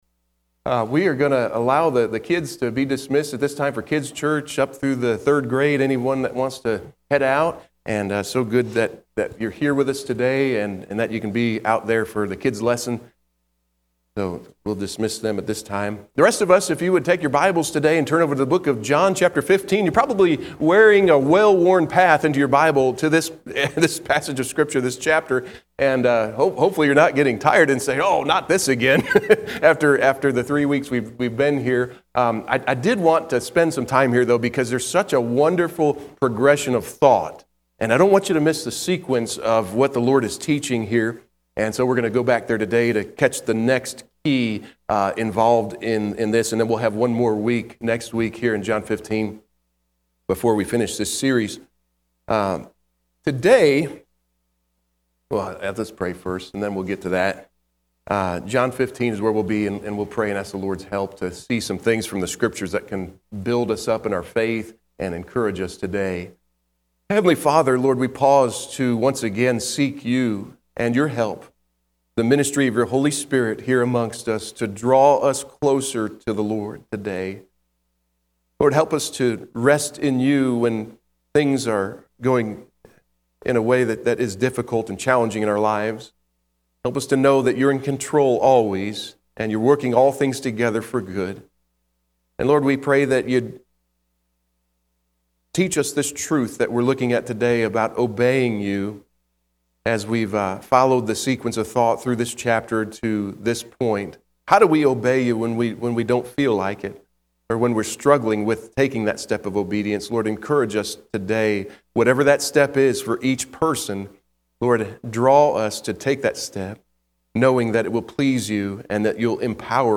AM Service